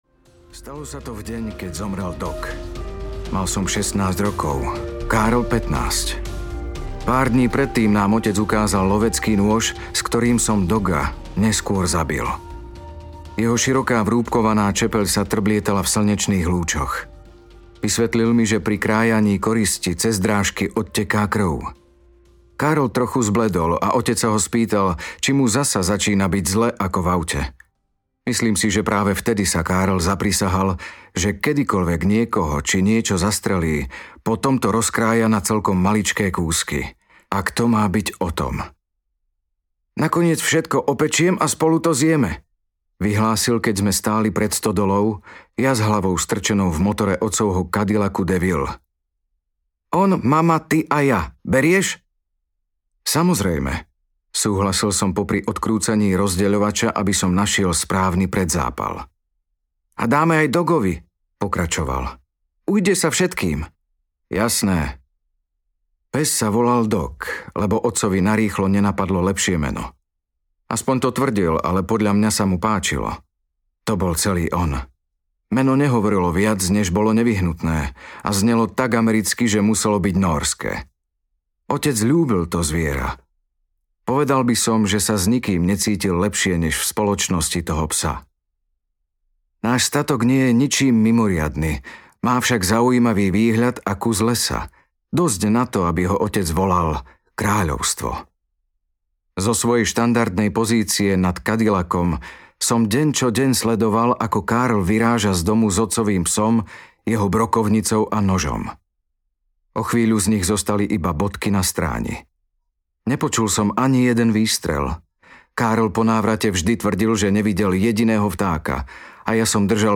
Kráľovstvo audiokniha
Ukázka z knihy